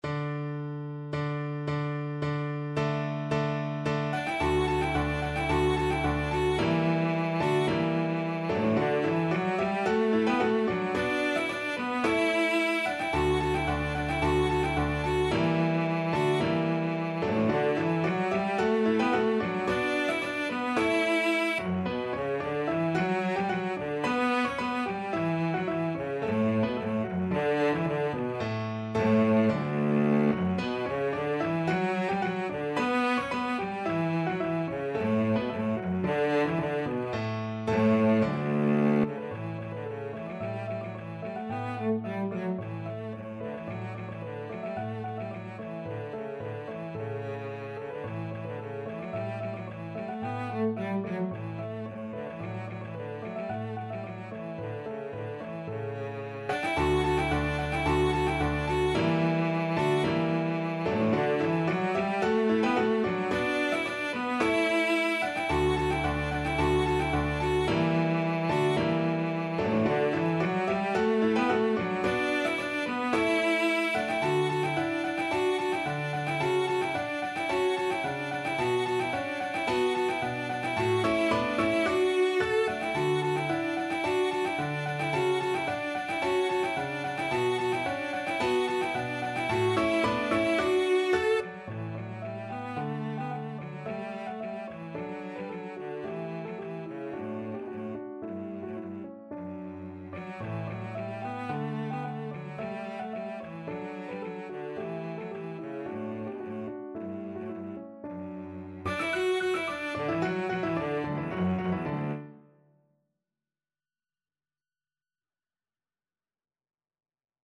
Cello version
2/2 (View more 2/2 Music)
= 110 Allegro di molto (View more music marked Allegro)
Classical (View more Classical Cello Music)